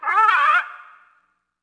Faerie Dragon Yells
精灵龙叫声